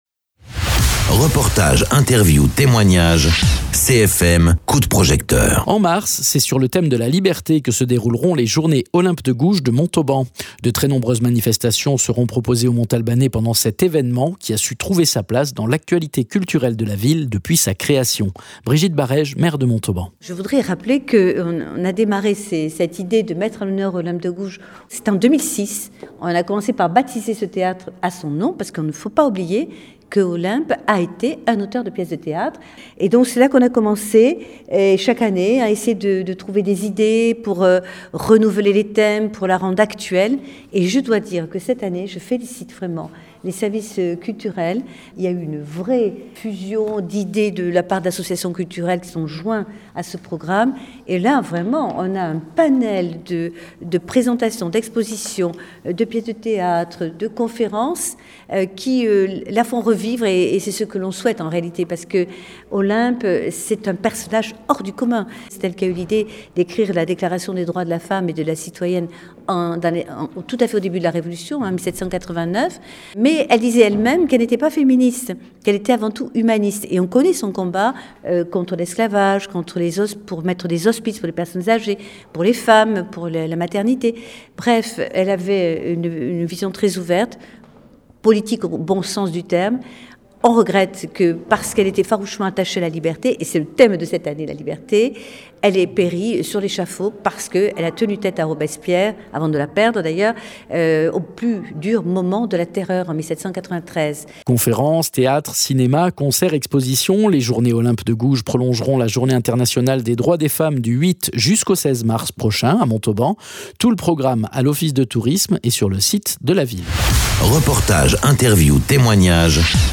Interviews
Invité(s) : Brigittes Barège maire de Montauban